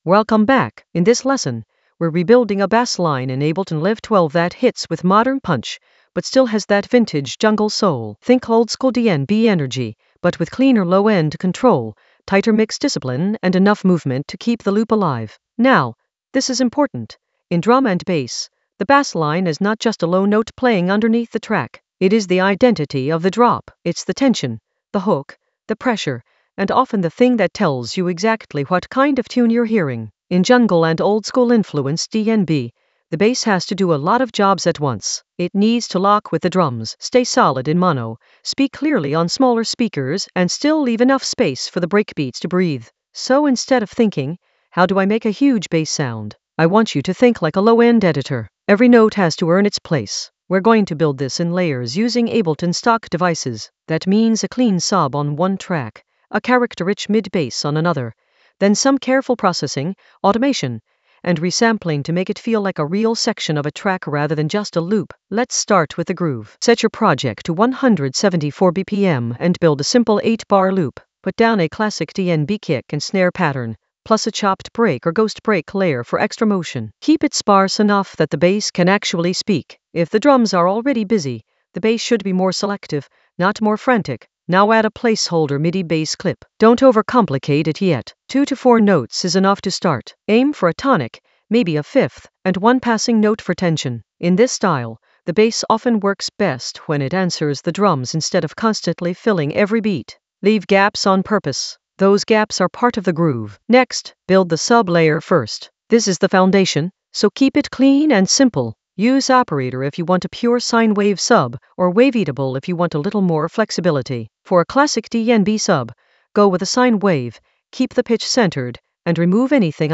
An AI-generated intermediate Ableton lesson focused on Bassline in Ableton Live 12: rebuild it with modern punch and vintage soul for jungle oldskool DnB vibes in the Basslines area of drum and bass production.
Narrated lesson audio
The voice track includes the tutorial plus extra teacher commentary.